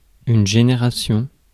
Ääntäminen
Ääntäminen France: IPA: [ʒe.ne.ʁa.sjɔ̃] Haettu sana löytyi näillä lähdekielillä: ranska Käännöksiä ei löytynyt valitulle kohdekielelle.